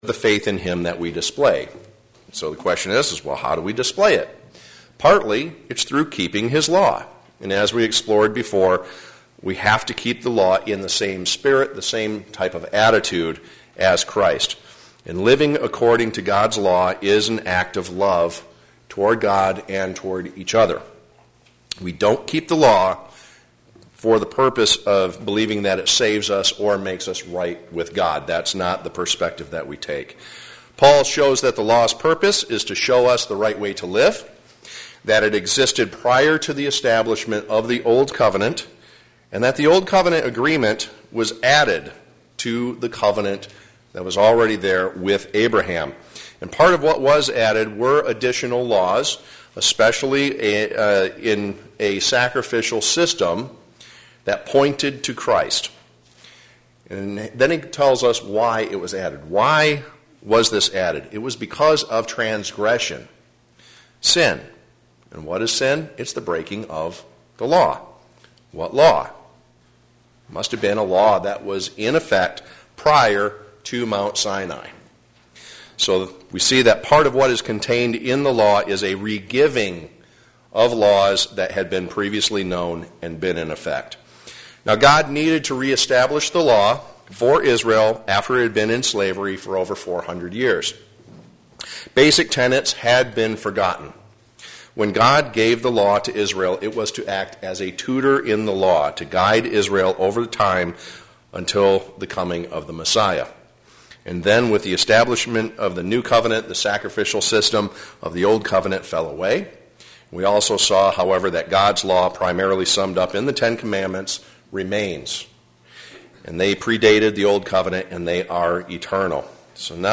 Galatians Bible Study: Part 8